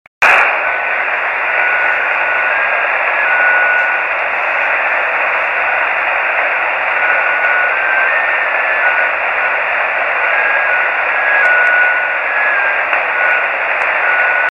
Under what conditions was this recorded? Grabaciones Rebote Lunar de KP4AO, Equipo: Yaesu FT-817. Antena: Yagi 9 elementos para 432 MHz en boom de madera de 1 m de largo.